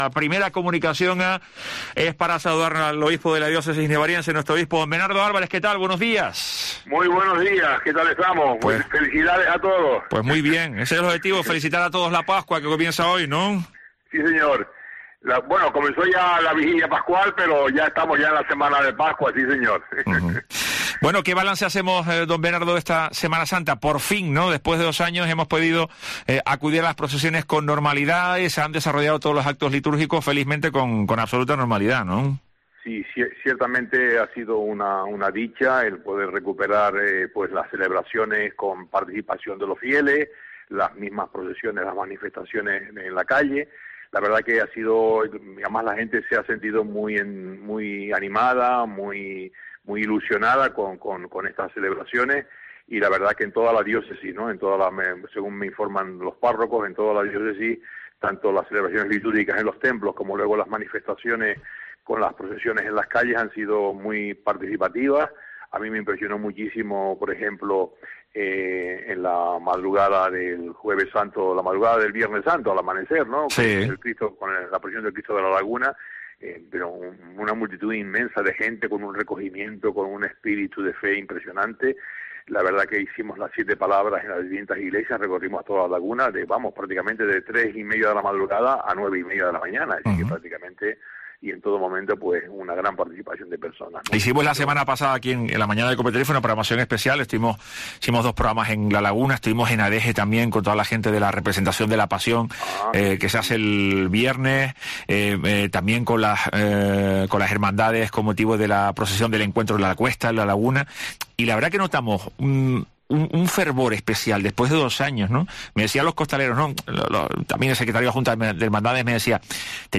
Bernardo Álvarez ha aprovechado los micrófonos de COPE Tenerife para felicitar la Pascua a los oyentes.